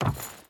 Footsteps / Wood / Wood Chain Run 2.ogg
Wood Chain Run 2.ogg